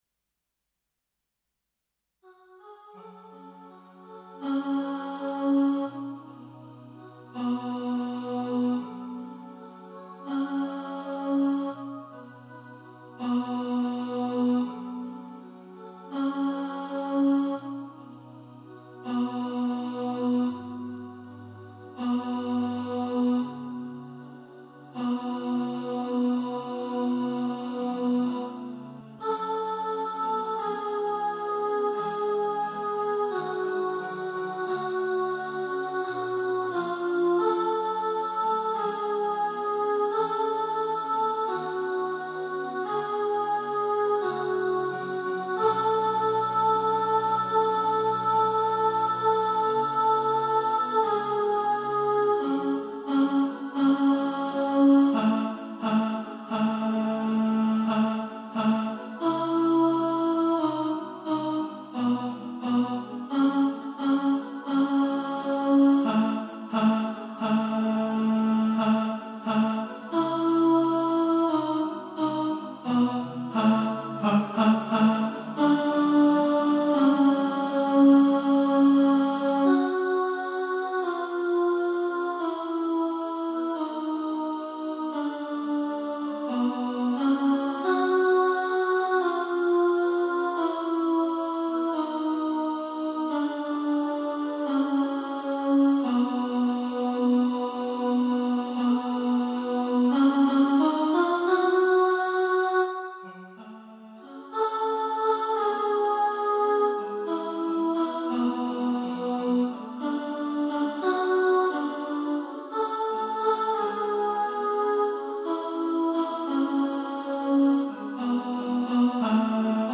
The-Riddle-Alto2.mp3